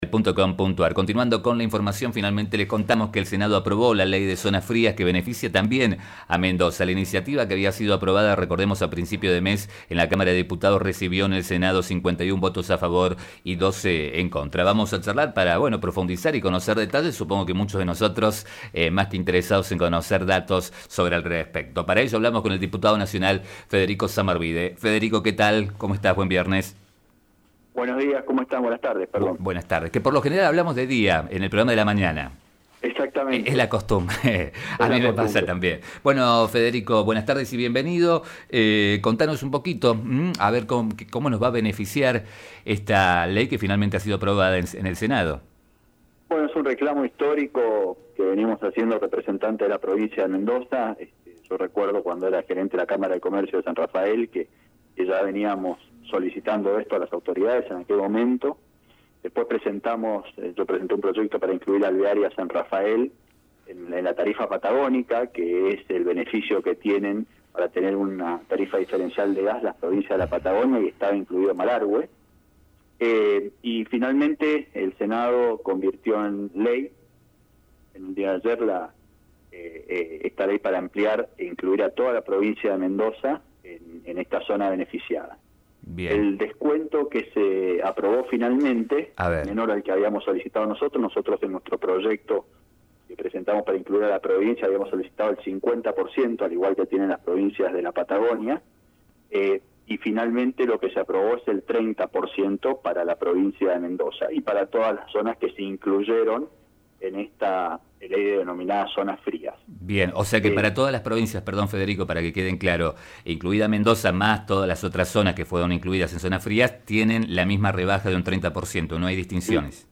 Sobre esta nueva ley de ampliación del Régimen de Zona Fría, que ya tenía media sanción en Diputados, el sanrafaelino y diputado nacional Federico Zamarbide (UCR) brindó detalles a FM Vos (94.5) y Diario San Rafael.